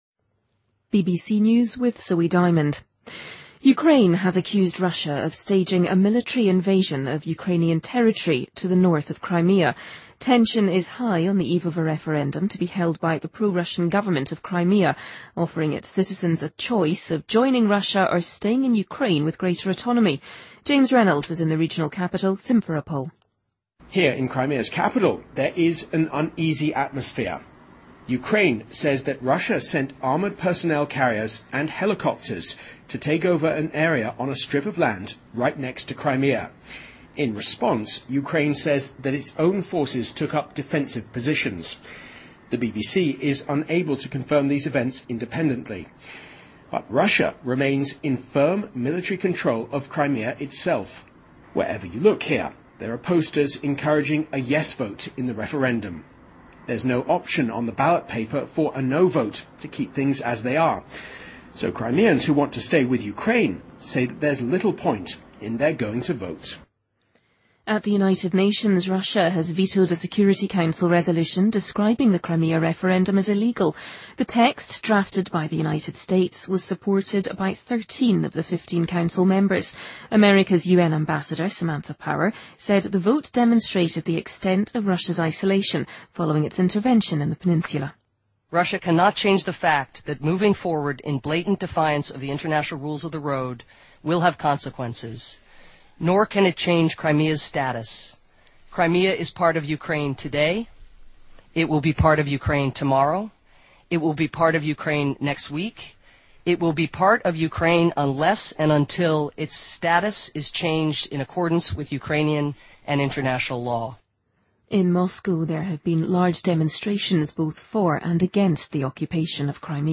BBC news,2014-03-16